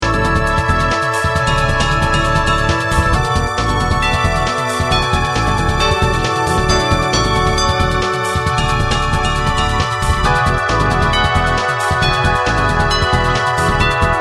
The harmonic language seems to lean towards Talk Talk's Spirit of Eden but I had fun with some Eighties electronic drums which reminded me of Stewart Copeland's theme music to The Equalizer.